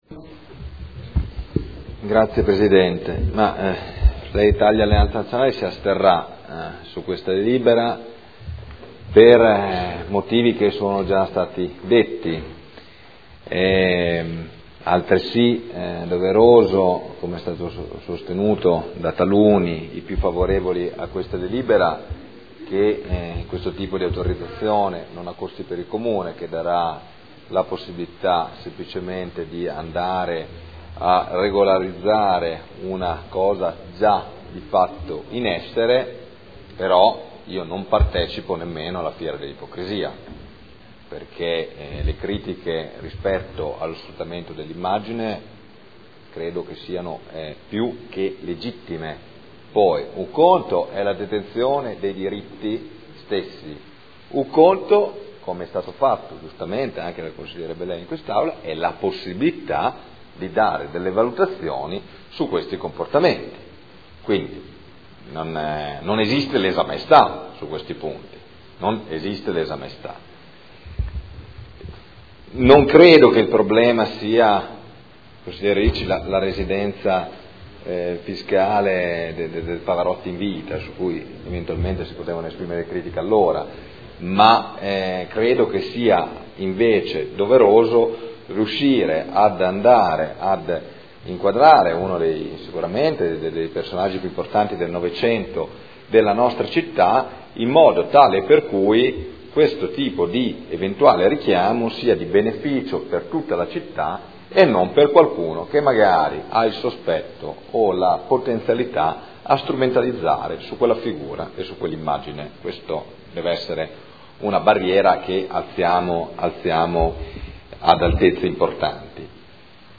Seduta del 31 marzo. Proposta di deliberazione: Proposta di progetto - Casa Museo del Maestro Luciano Pavarotti – Stradello Nava – Z.E. 2400 – Nulla osta in deroga agli strumenti urbanistici comunali – Art. 20 L.R. 15/2013. Dichiarazioni di voto